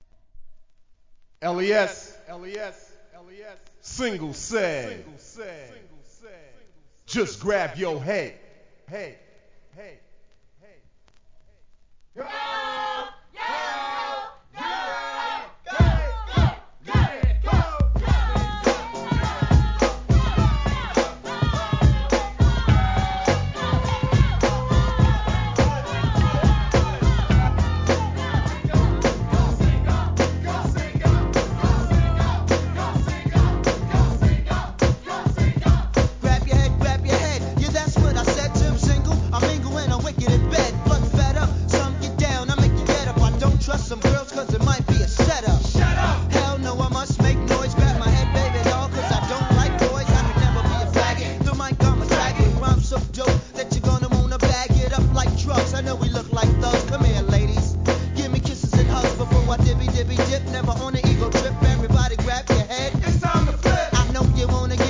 HIP HOP/R&B
定番ブレイクにB級感が堪らないガヤなど素晴らしい!!!